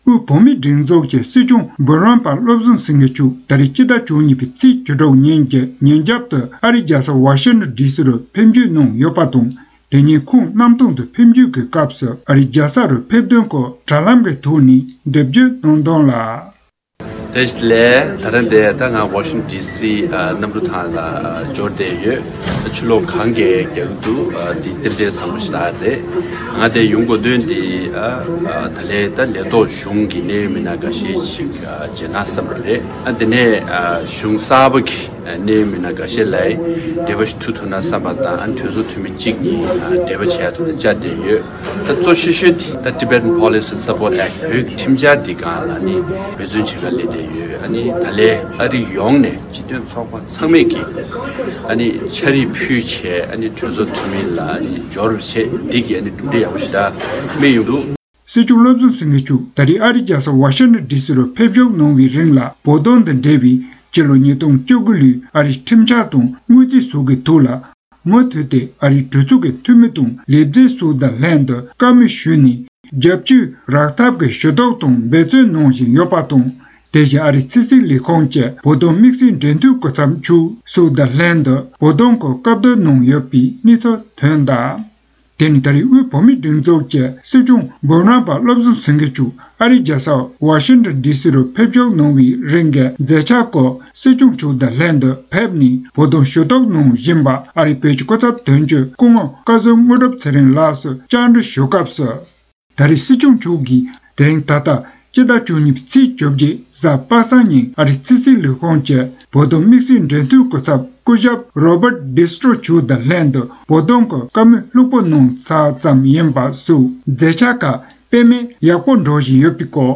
0:00 / 0:00 དབུས་བོད་མིའི་སྒྲིག་འཛུགས་ཀྱི་སྲིད་སྐྱོང་འབུམ་རམ་པ་བློ་བཟང་སེངྒེ་མཆོག་ད་རེས་སྤྱི་ཟླ་༡༢ཚེས་༡༦ནས་ཨ་རིའི་རྒྱལ་ས་ཝ་ཤིང་ཊོན་ཌི་སི་རུ་ཕེབས་ནས་གཟའ་མཇུག་བར་བཞུགས་རིང་བོད་དོན་དང་འབྲེལ་བའི་སྤྱི་ལོ་༢༠༡༩འི་ཨ་རིའི་ཁྲིམས་འཆར་དང་དངུལ་བརྩིས་སོགས་ཀྱི་ཐོག་ལ་མུ་མཐུད་དེ་ཨ་རིའི་གྲོས་ཚོགས་ཀྱི་འཐུས་མི་དང་ལས་བྱེད་སོགས་དང་ལྷན་དུ་བཀའ་མོལ་ཞུས་ནས་རྒྱབ་སྐྱོར་ཐོབ་ཐབས་ཀྱིས་ཞུ་གཏུགས་དང་འབད་བརྩོན་གནང་བཞིན་ཡོད་པ་དང་། དེ་བཞིན་ཨ་རིའི་ཕྱི་སྲིད་ལས་ཁུངས་ཀྱི་བོད་དོན་དམིགས་བསལ་འབྲེལ་མཐུད་སྐུ་ཚབ་མཆོག་སོགས་ཀྱི་ལྷན་དུ་བོད་དོན་སྐོར་བཀའ་བསྡུར་གནང་ཡོད་པའི་སྐོར་ཨ་རི་བོད་ཀྱི་སྐུ་ཚབ་དོན་གཅོད་སྐུ་ངོ་བཀའ་ཟུར་དངོས་སྒྲུབ་ཚེ་རིང་ལགས་སུ་བཅའ་འདྲི་ཞུས་པ་གསན་རོགས་ཞུ།